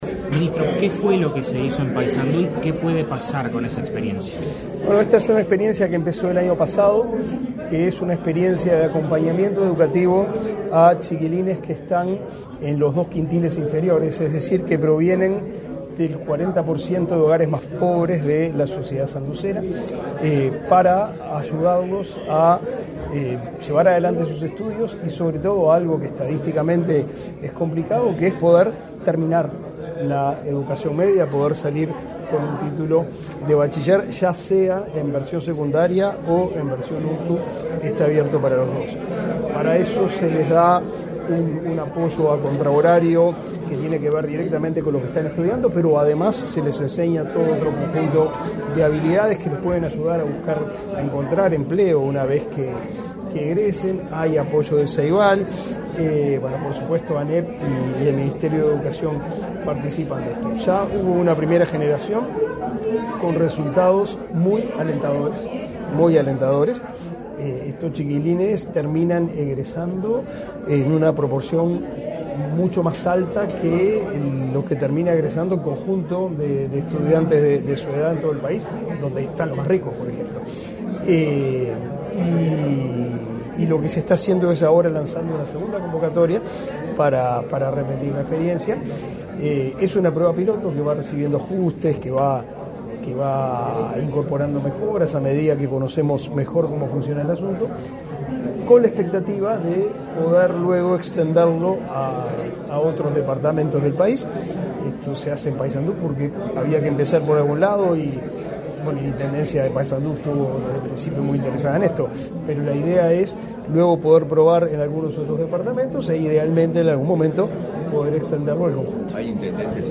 Declaraciones a la prensa del ministro de Educación y Cultura, Pablo da Silveira
Con la presencia del presidente de la República, Luis Lacalle Pou, se realizó, este 26 de junio, la firma de convenio entre la Oficina de Planeamiento y Presupuesto (OPP), el Instituto Nacional de Empleo y Formación Profesional (Inefop) y la Intendencia de Paysandú, que posibilitará finalizar la educación media superior a más de 70 estudiantes. Tras el evento, el ministro de Educación y Cultura, Pablo da Silveira, realizó declaraciones a la prensa.